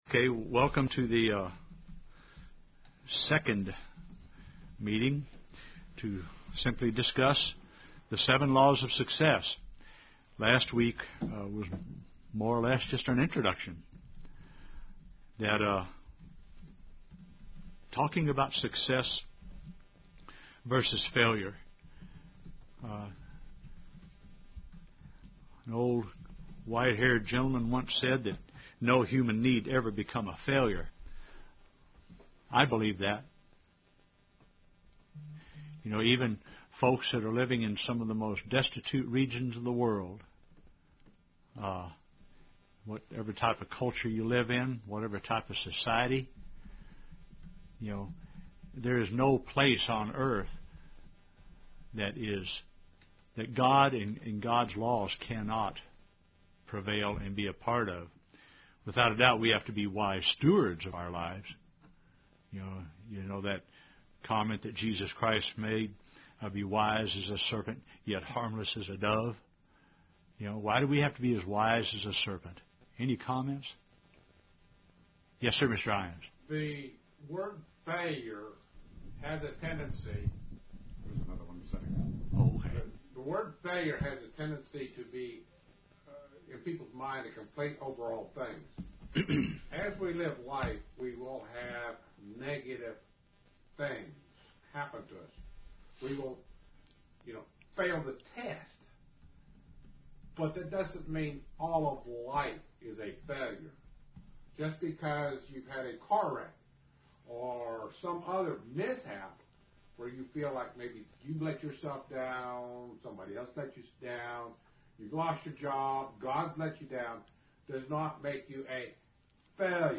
There are seven vital keys to live a successful life. This is the introductory message to those seven laws to success, given in a Young Adult Bible Study.
UCG Sermon Studying the bible?